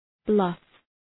Shkrimi fonetik {blʌf}